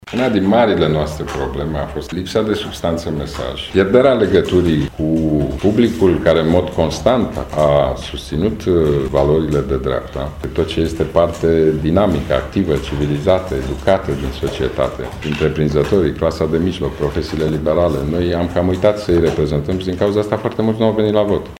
Aflat în turneu prin țară, Ludovic Orban, a explicat într-o conferință de presă situația actuală prin care trece PNL, arătând principalele cauze care au dus la pierderea alegerilor parlamentare din decembie 2016: